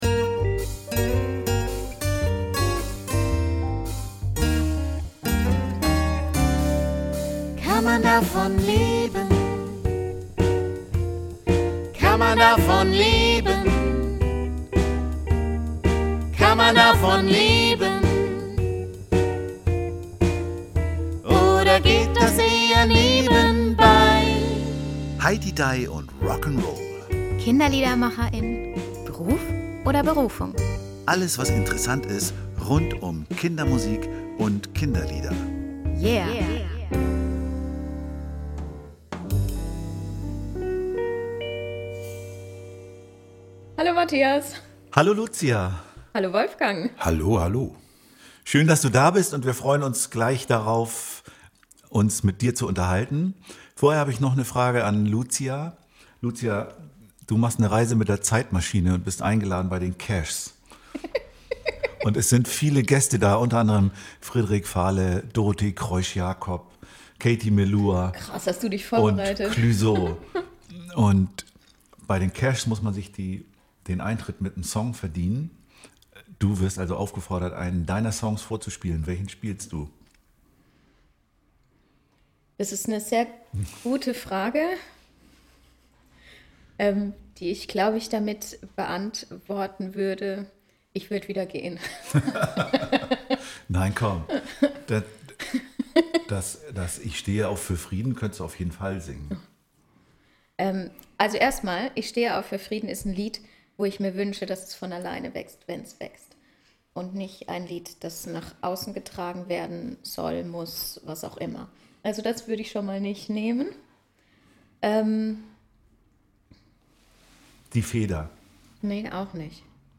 Worauf man achten sollte beim Umgang mit Verlagen verrät er im Gespräch in dieser Folge.